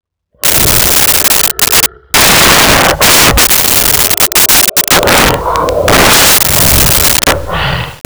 Lion
Lion.wav